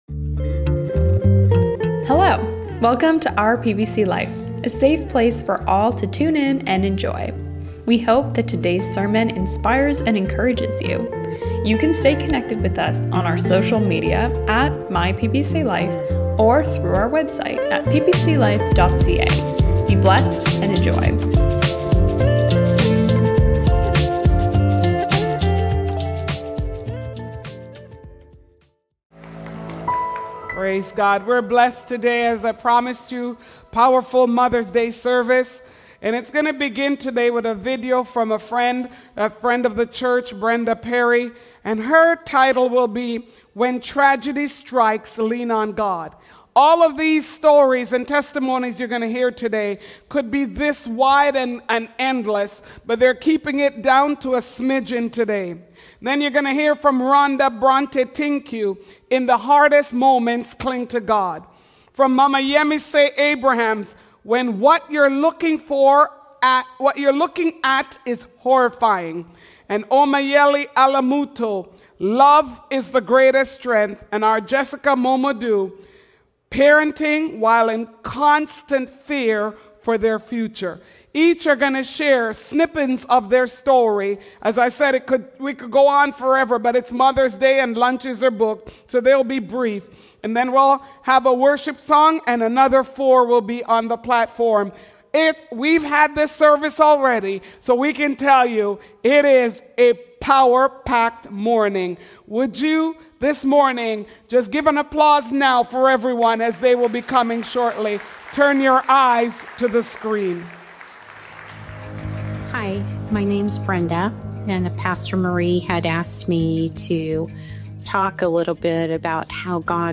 Today we have a special service, with stories and testimonies, advice and encouragement from 9 different Mothers.